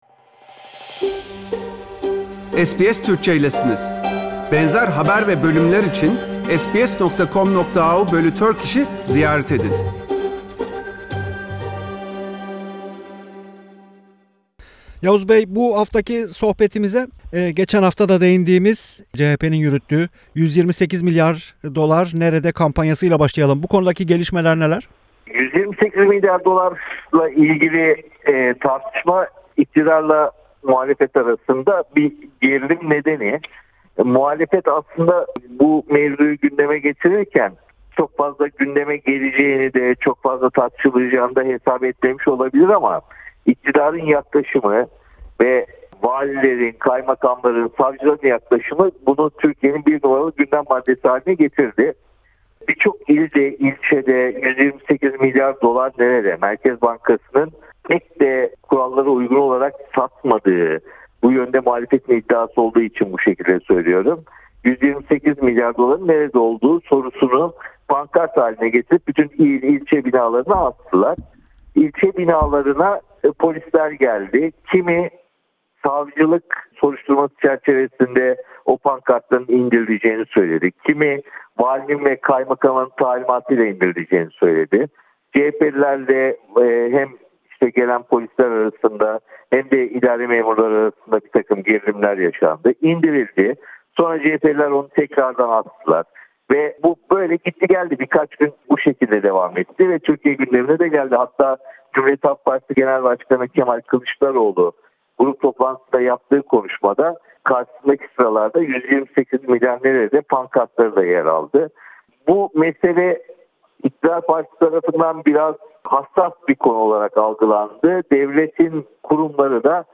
Gazeteci Yavuz Oğhan Türkiye'nin son günlerde öne çıkan konularını değerlendirdi.